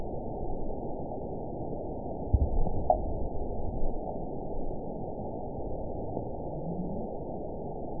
event 917203 date 03/23/23 time 17:20:02 GMT (2 years, 1 month ago) score 9.39 location TSS-AB03 detected by nrw target species NRW annotations +NRW Spectrogram: Frequency (kHz) vs. Time (s) audio not available .wav